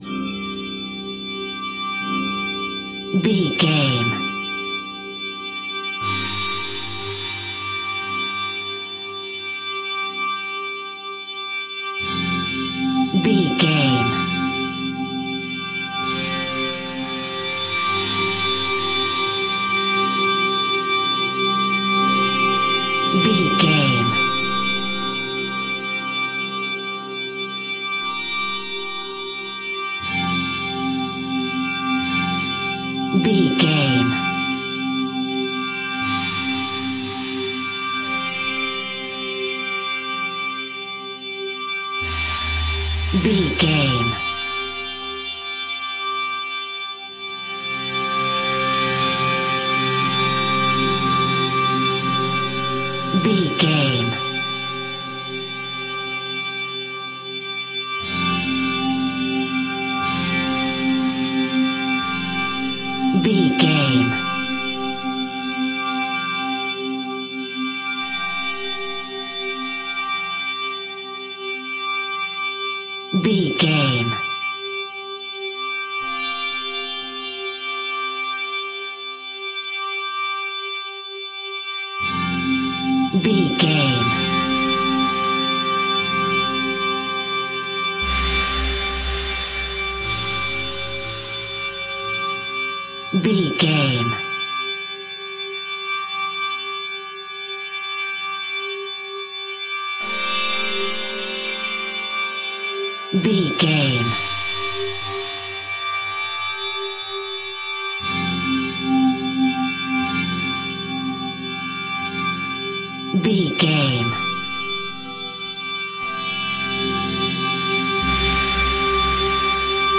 Atonal
Slow
mystical
middle east ambience
Synth Pads
ethnic percussion